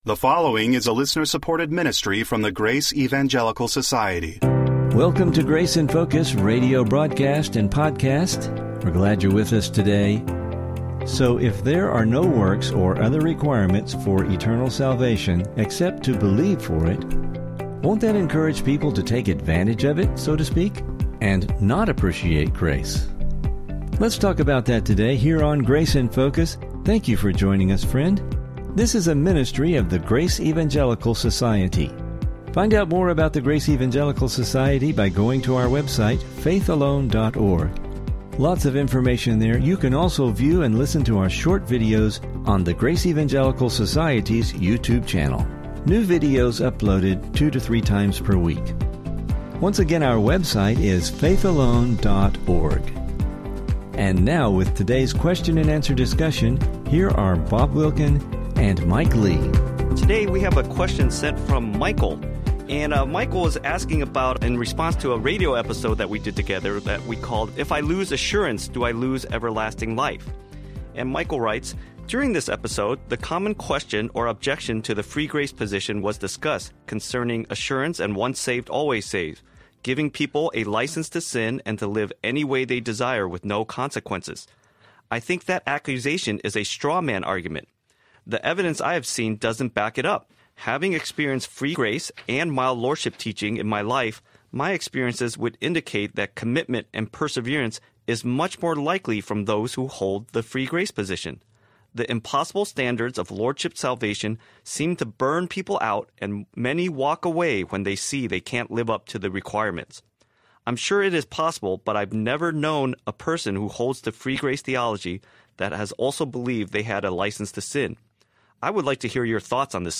Please listen for an interesting discussion and lessons related to this topic.